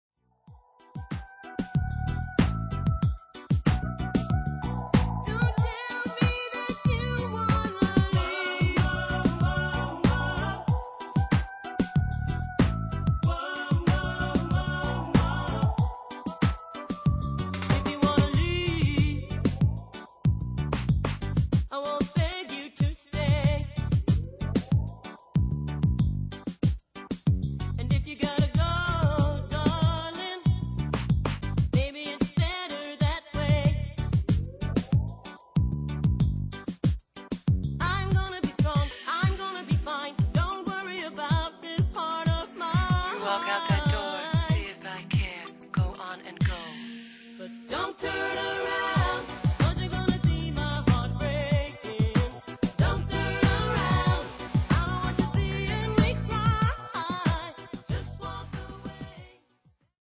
NOTE: Vocal Tracks 1 Thru 8